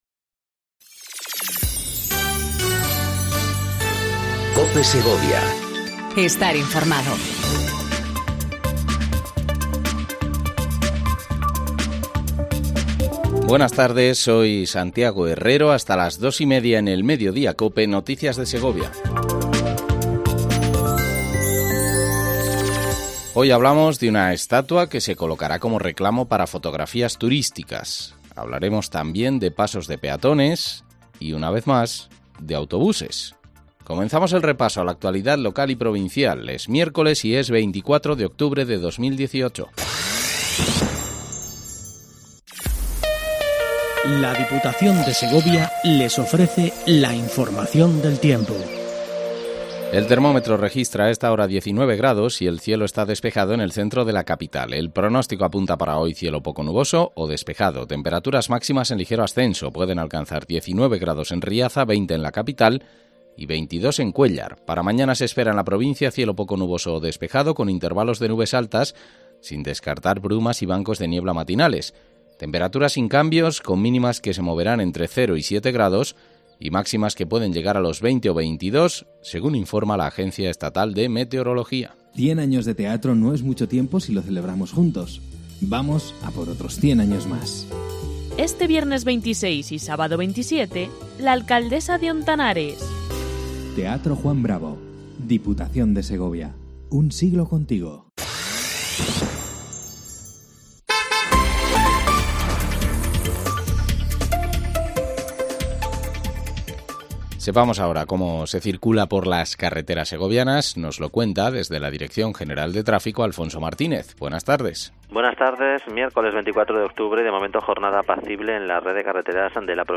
INFORMATIVO MEDIODÍA COPE SEGOVIA 14:20 DEL 24/10/18